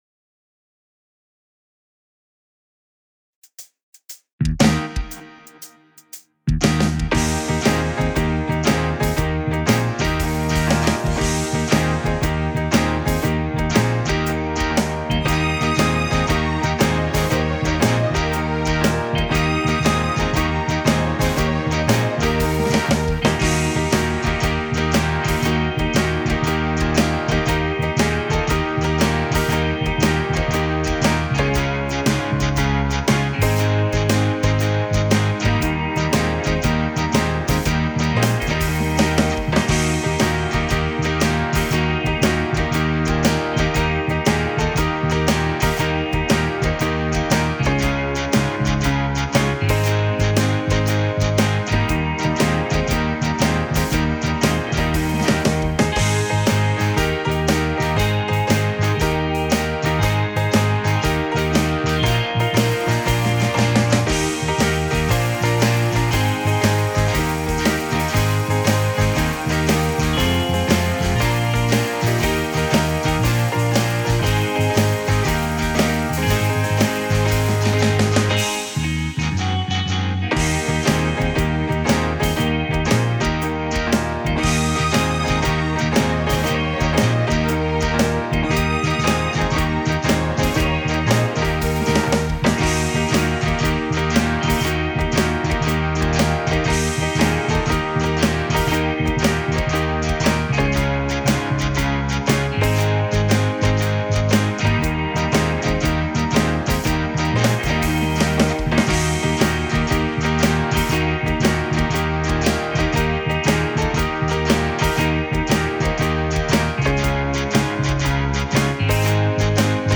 Langsameres Tempo